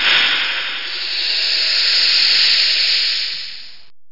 SYNWOOSH.mp3